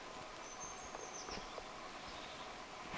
töyhtötiainen
toyhtotiainen.mp3